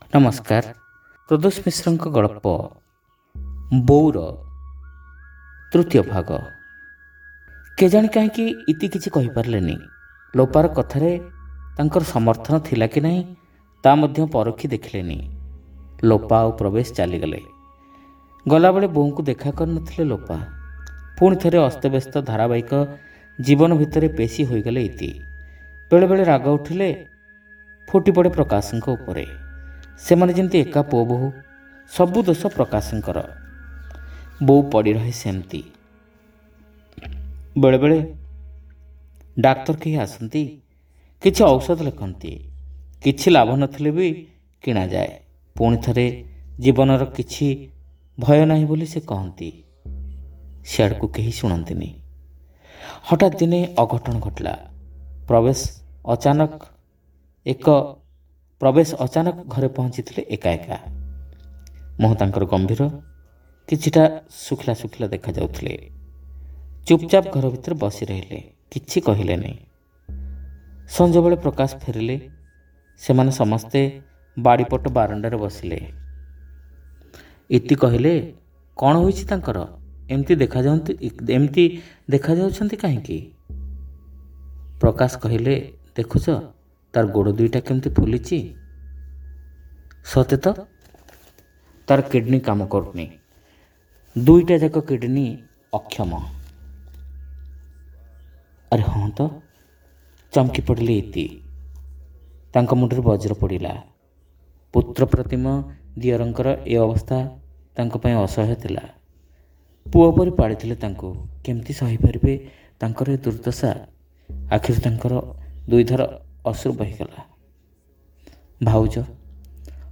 ଶ୍ରାବ୍ୟ ଗଳ୍ପ : ବୋଉ (ତୃତୀୟ ଭାଗ)